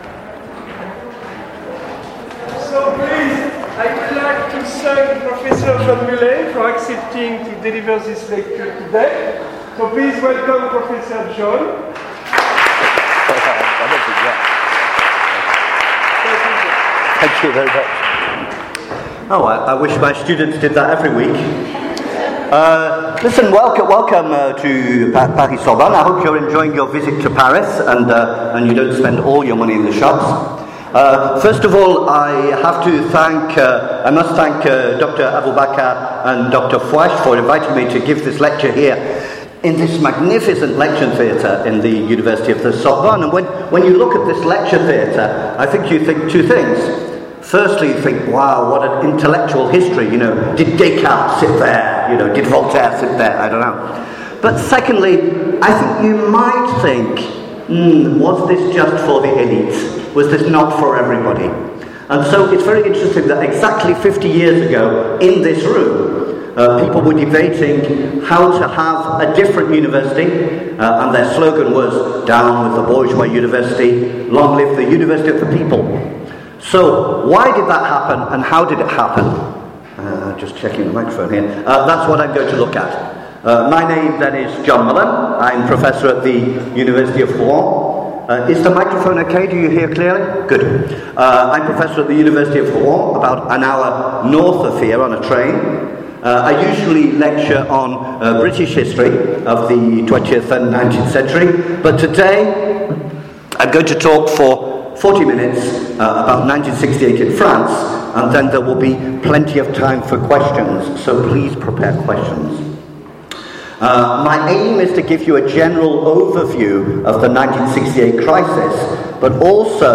This lecture, given for a group of students from the United Arab Emirates, looks at what really happened, and opens up a series of important questions, which we don't always have answers for. Why did workers support the students ?